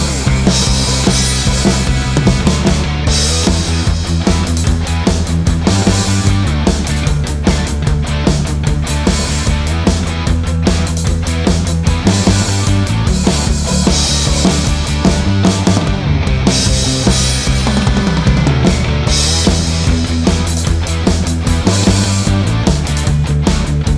With Intro and Backing Vocals Rock 4:52 Buy £1.50